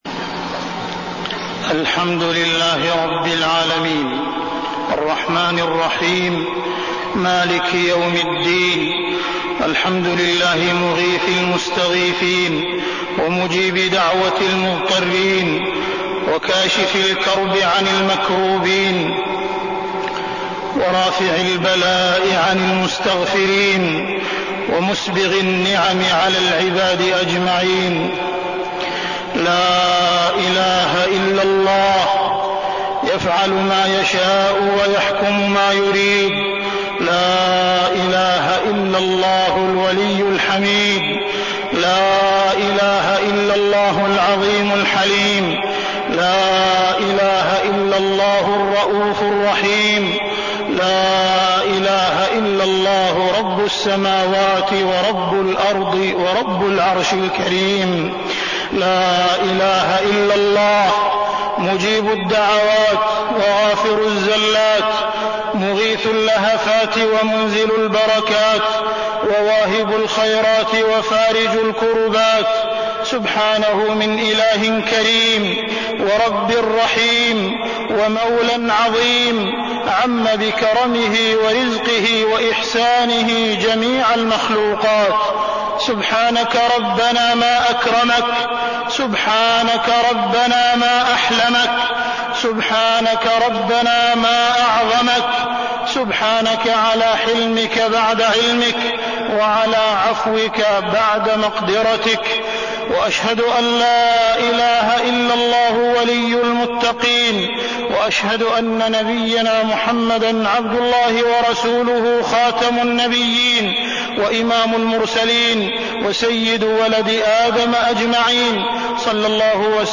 تاريخ النشر ٢٣ جمادى الآخرة ١٤١٧ هـ المكان: المسجد الحرام الشيخ: معالي الشيخ أ.د. عبدالرحمن بن عبدالعزيز السديس معالي الشيخ أ.د. عبدالرحمن بن عبدالعزيز السديس المعاصي من أسباب منع القطر